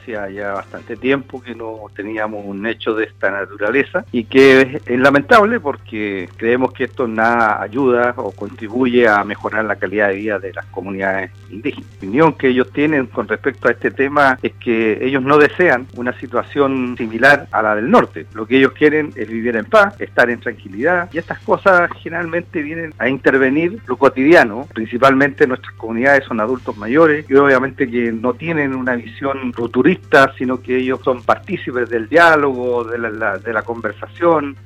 En conversación con Radio Sago, el edil de la comuna costera, Bernardo Candia, se refirió al ataque incendiario que dejó cuatro maquinas forestales quemadas este martes.